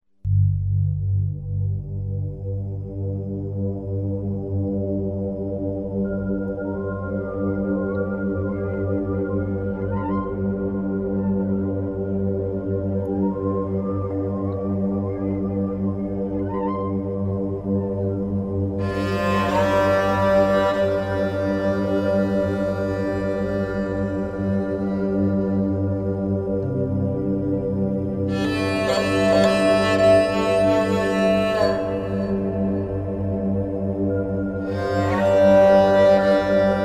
Эсраж
Это дает звук очень похожий на сарангу но, играть на нем становится легче.
esraj1.mp3